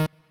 left-synth_chord51.ogg